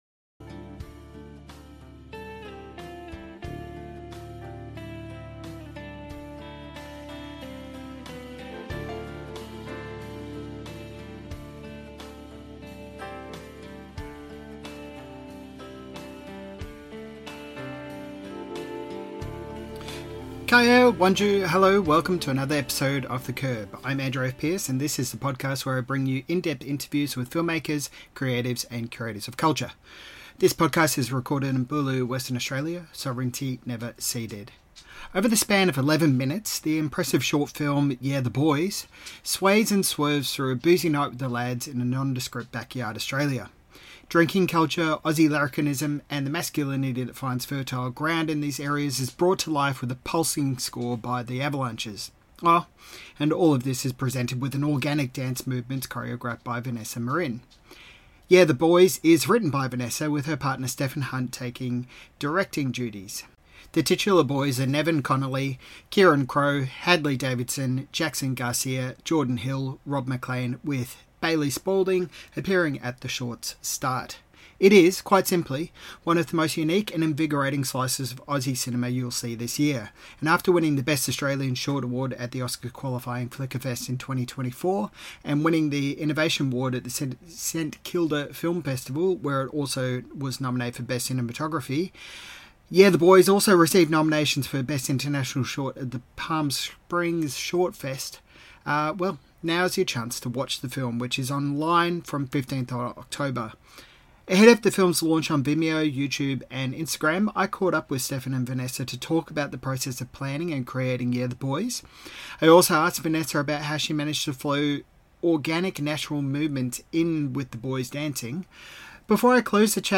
Interview - The Curb